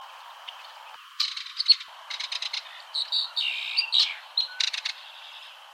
Belted kingfisher
The belted kingfisher makes a wild, rattling call.
belted-kingfisher-call.mp3